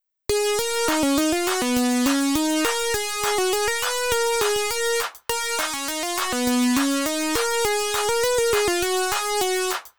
この曲のキー、”F♯”です。